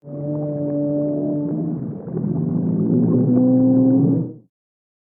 Manatee Groan
Manatee Groan is a free sfx sound effect available for download in MP3 format.
yt_RWuC46soPd8_manatee_groan.mp3